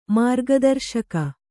♪ mārga darśaka